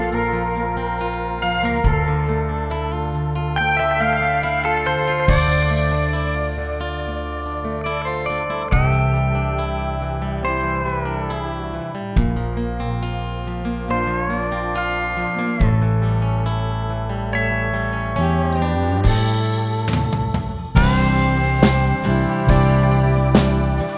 no Backing Vocals Country (Male) 3:42 Buy £1.50